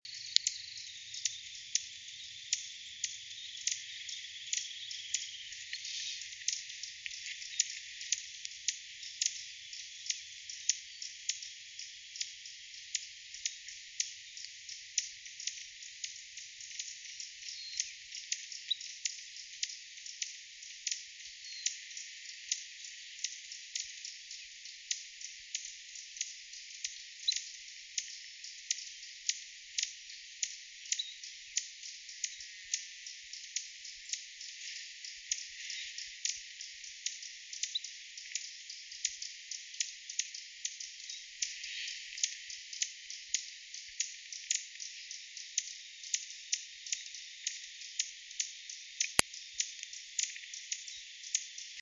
15-3麟趾山口2012march28栗背林鴝m1.mp3
物種名稱 栗背林鴝 Tarsiger johnstoniae
錄音地點 南投縣 信義鄉 玉山麟趾山口
9 錄音環境 灌木叢 發聲個體 行為描述 雄鳥 錄音器材 錄音: 廠牌 Denon Portable IC Recorder 型號 DN-F20R 收音: 廠牌 Sennheiser 型號 ME 67 標籤/關鍵字 備註說明 MP3檔案 15-3麟趾山口2012march28栗背林鴝m1.mp3